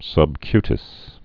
(sŭb-kytĭs)